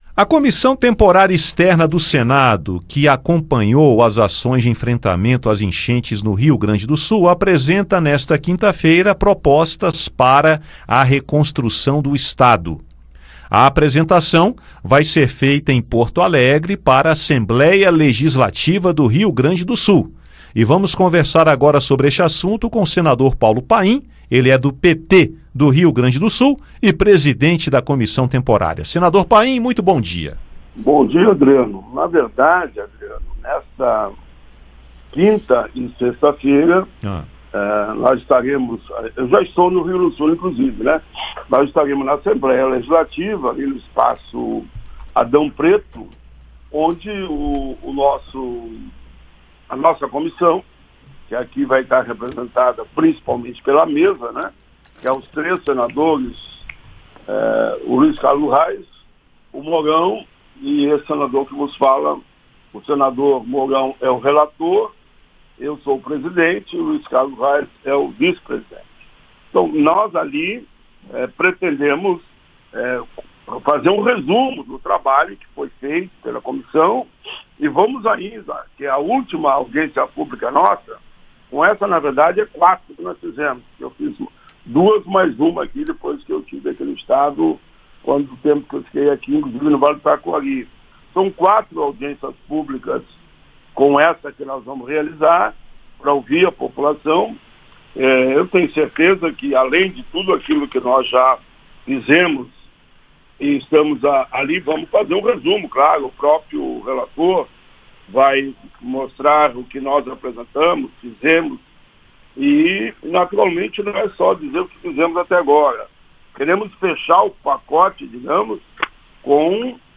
O presidente da comissão, senador Paulo Paim (PT-RS), comenta a construção das propostas em audiências com participação de autoridades locais, lideranças comunitárias, defensores públicos, ministros do TCU e especialistas. Paim destaca algumas das propostas e explica como será o encaminhamento.